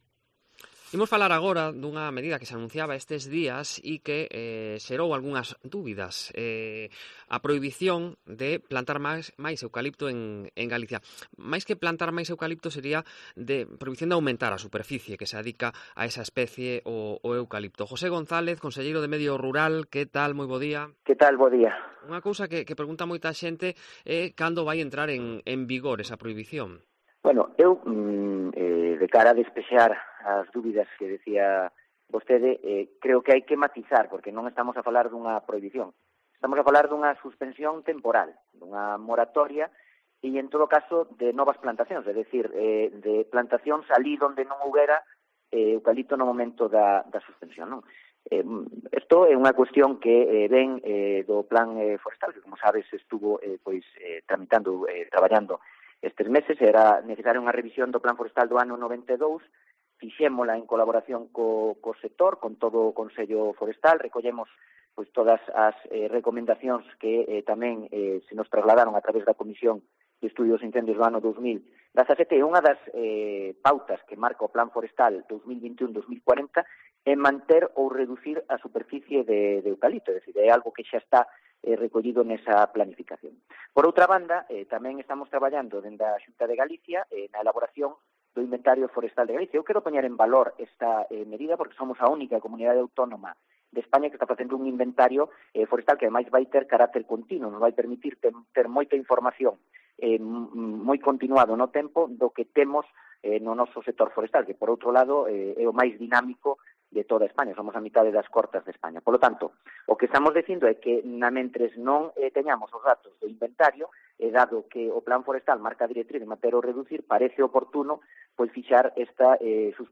La suspensión temporal de las plantaciones de eucaliptos plantean dudas a los propietarios, las resolvemos con el conselleiro de Medio Rural
Ante las dudas que surgen a los productores de ese tipo de madera tan apreciada en el mercado hemos preguntado al conselleiro de Medio Rural del gobierno autonómico.